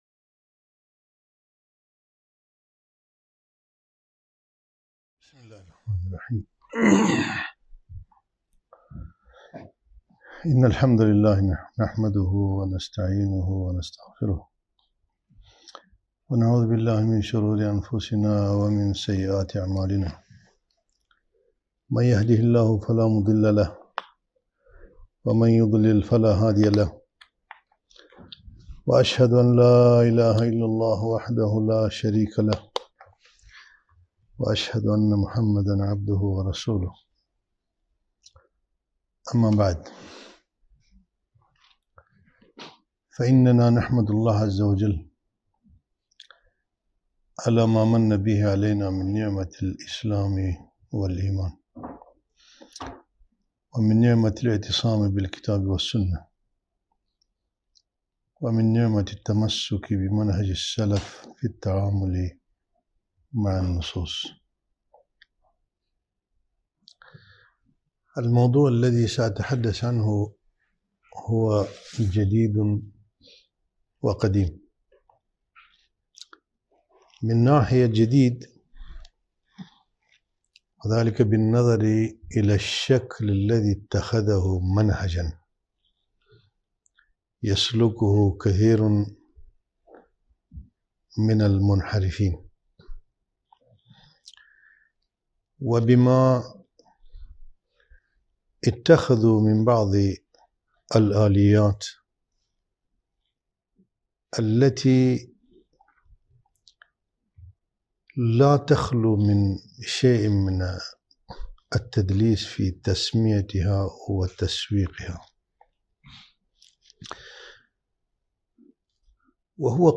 محاضرة - القراءة المعاصرة للنص الشرعي عرض ونقد